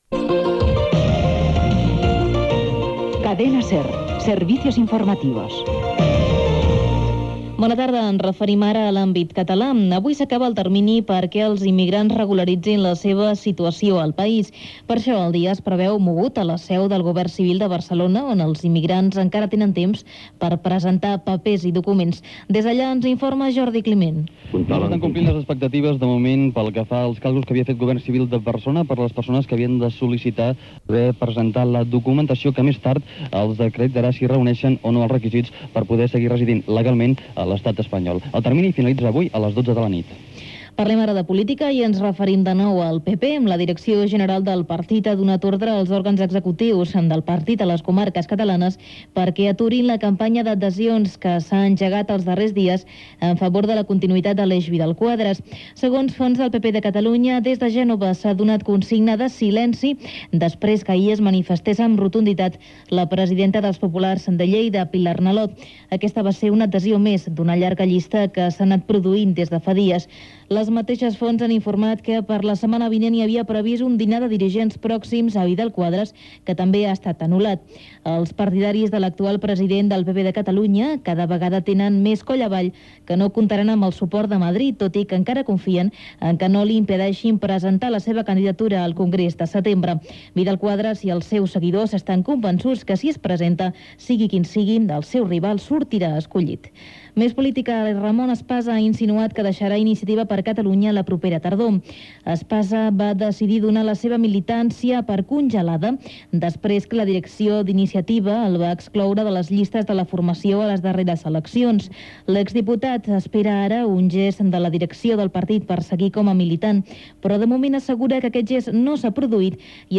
Careta, regulació de residència dels immigrants, Aleix Vidal Quadras, Iniciativa per Catalunya, accidents laborals, competicions europees de futbol, indicatiu de la ràdio
Informatiu